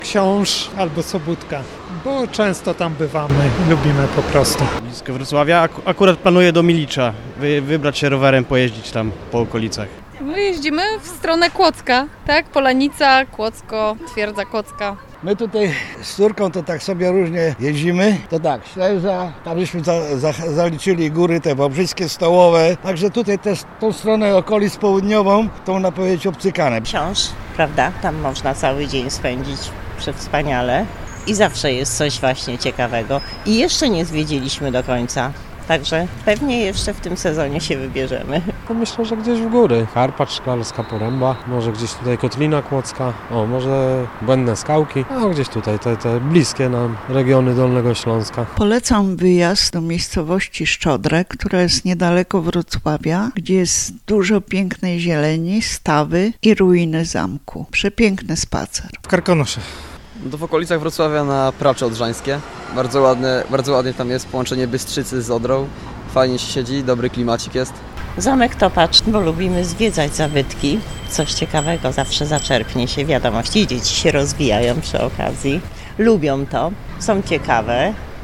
Respondenci zdradzają, dokąd oni udaliby się na jednodniowy wypad za miasto.